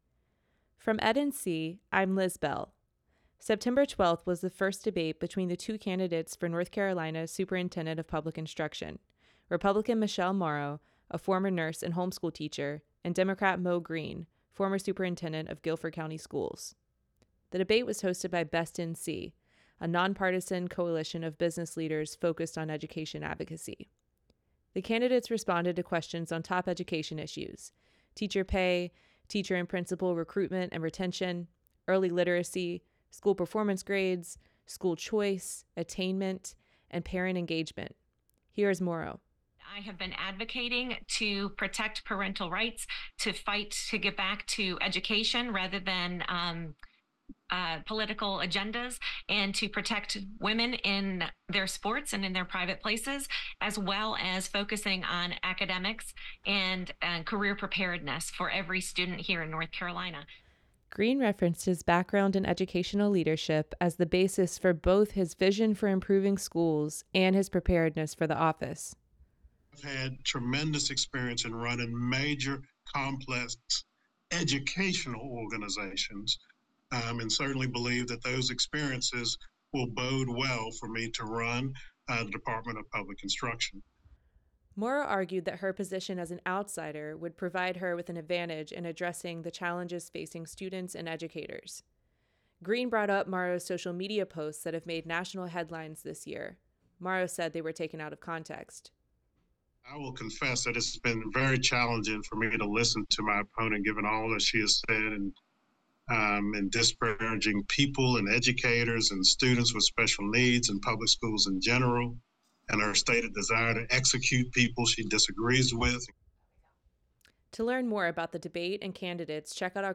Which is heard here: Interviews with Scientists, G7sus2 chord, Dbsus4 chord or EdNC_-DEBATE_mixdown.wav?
EdNC_-DEBATE_mixdown.wav